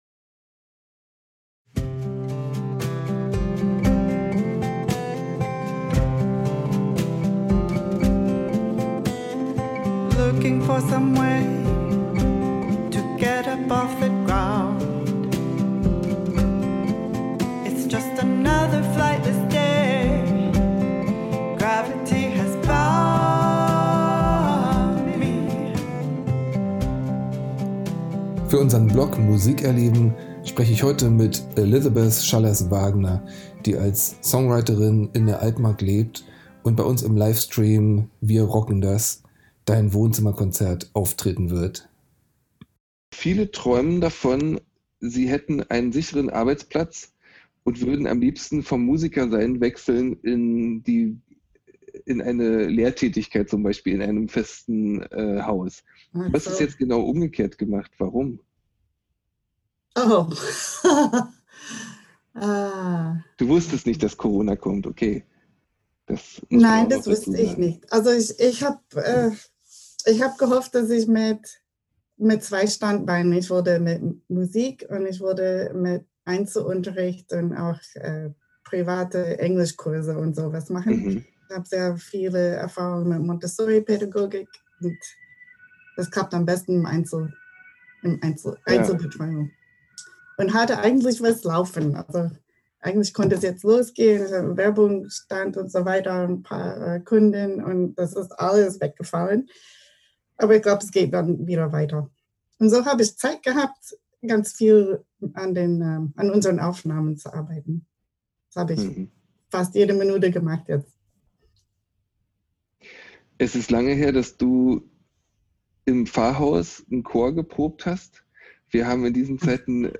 Podcast-Interview